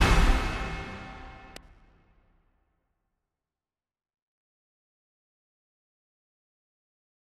MDMV3 - Hit 18.wav